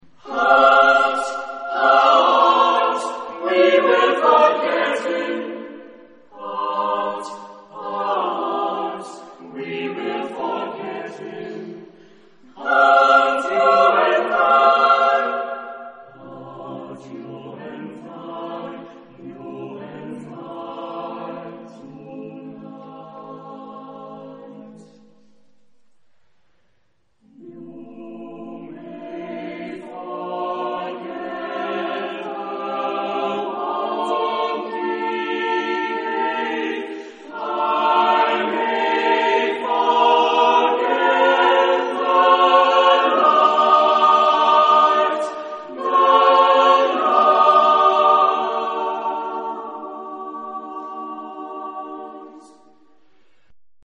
Genre-Style-Forme : Profane ; contemporain
Type de choeur : SATB  (4 voix mixtes )